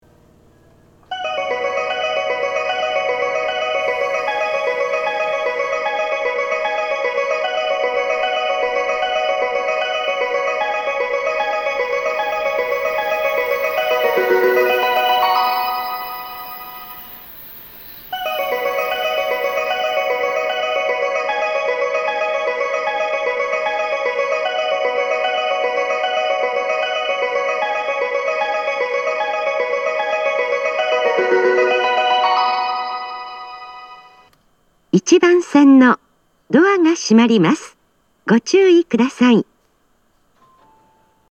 発車メロディーの音量は小さいです。
1.9コーラス
※新幹線の通過音が被っています。